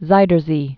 (zīdər zē, zā, zoidər zā)